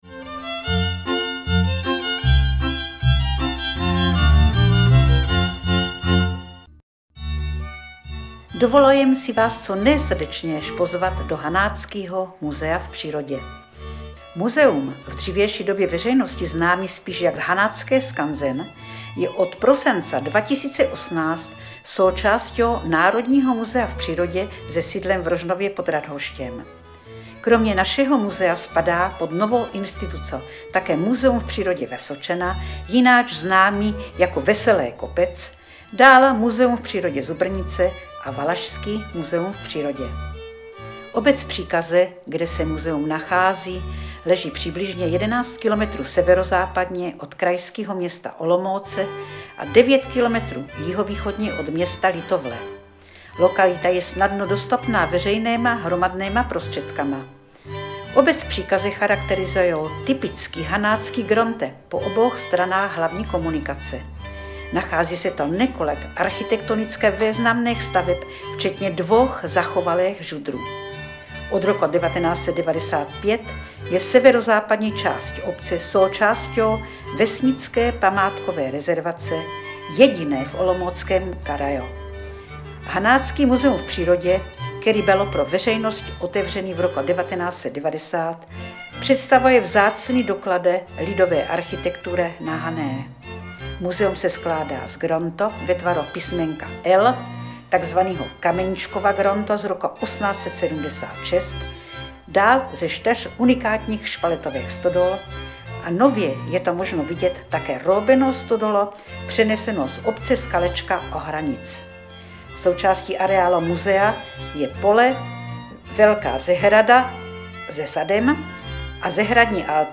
SPUSTIT/STÁHNOUT audio pozvánku do tohoto Hanáckyho skanzenu přímo v hanáčtině.